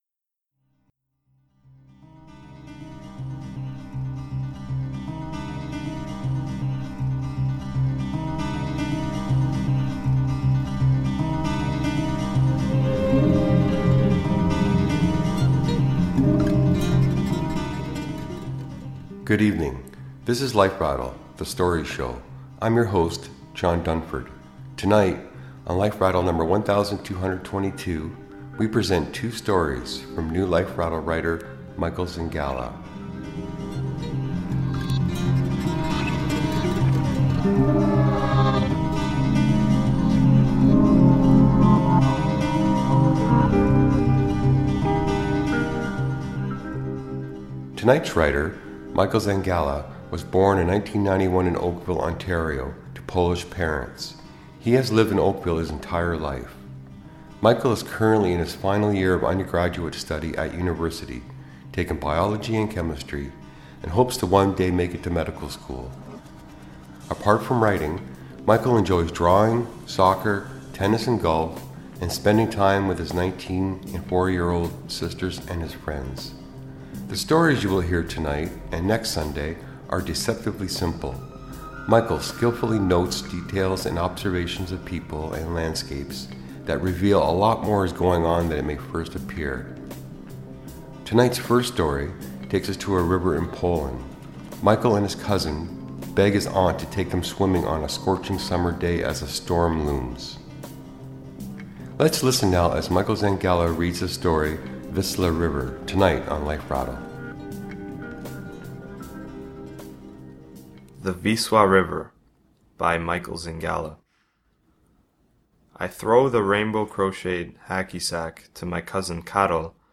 Note: Tonight's second story contains strong language and situations.